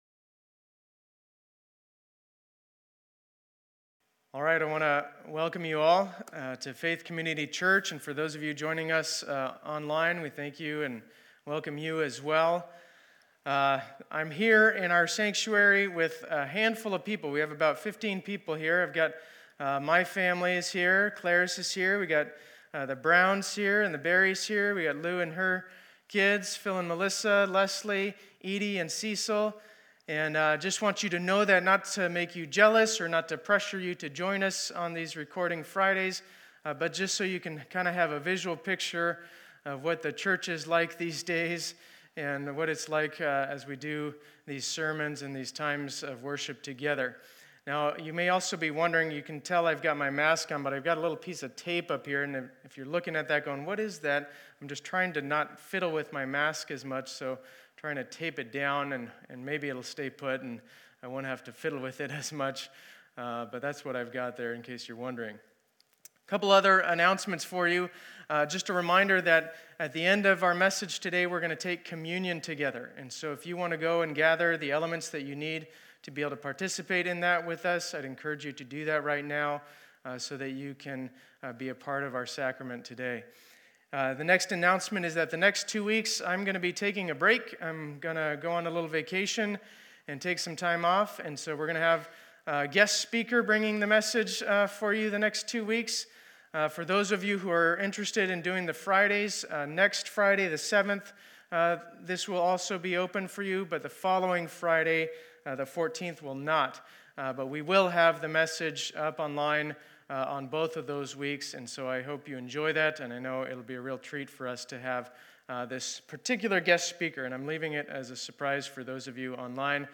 Sermon Intro, Teachi…